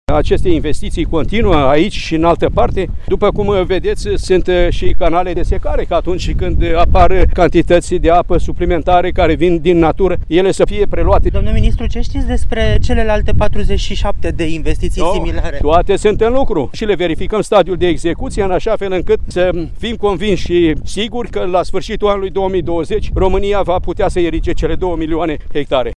Sistemul naţional de irigaţii ar urma să fie reparat în întregime până în anul 2020, a dat asigurări ieri ministrul Agriculturii, Petre Daea. El a făcut acestă declaraţie pentru corespondentul RR, după ce a vizitat staţia de irigaţii din localitatea Borcea, judeţul Călăraşi, care va asigura apă pentru 11 mii de hectare de teren agricol.